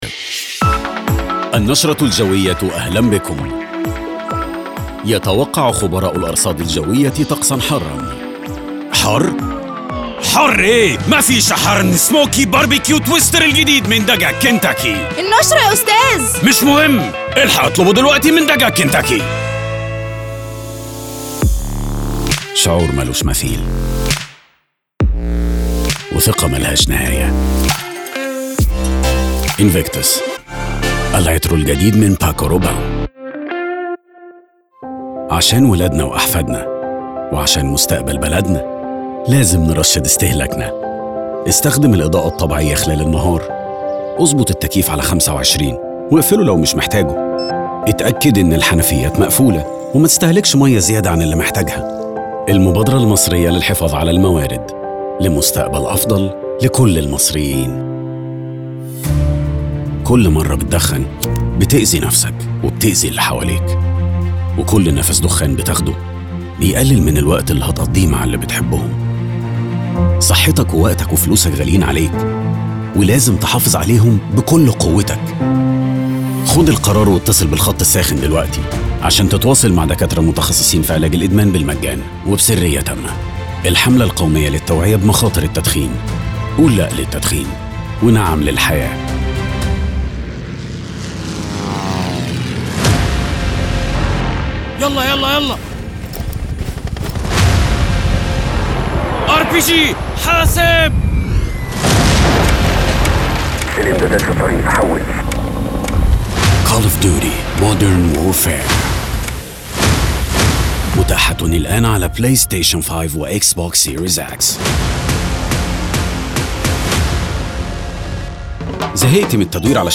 Male Voices